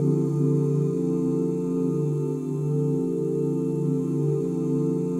OOHD SUS13.wav